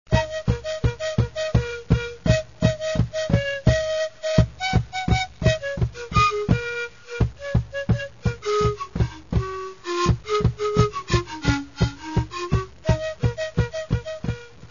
Flauta de pà